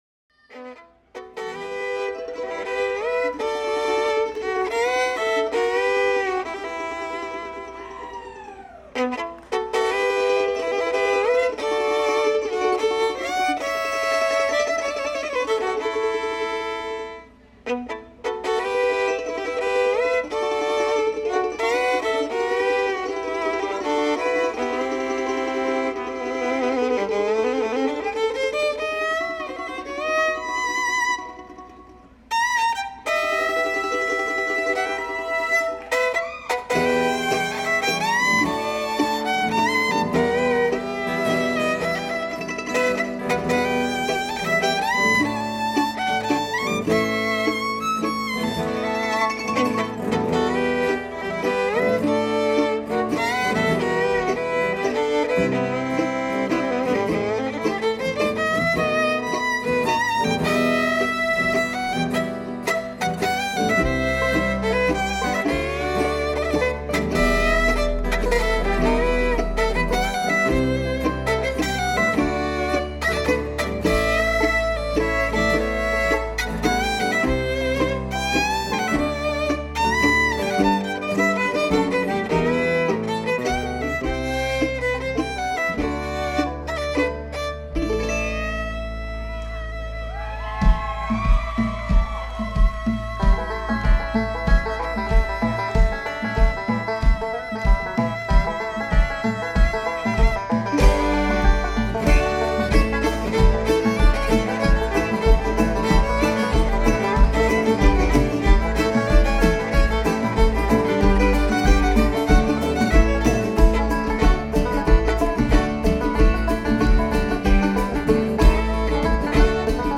Banjo, Guitar, Harmonica, and Vocals
Mandolin, Mondola and Vocals
Fiddle and Vocals
Bass, Guitar and Vocals
Percussion, Guitar, Dobro, Banjo, Whistling, Vocals,